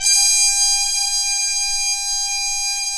Index of /90_sSampleCDs/Roland L-CD702/VOL-2/BRS_Harmon Sect/BRS_Harmon Tps M